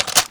ar_mag_load.ogg